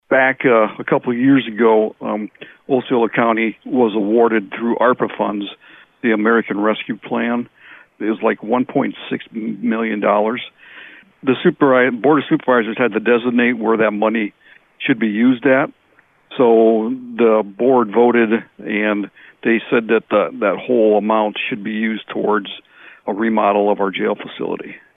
He tells us why this issue is coming up now specifically.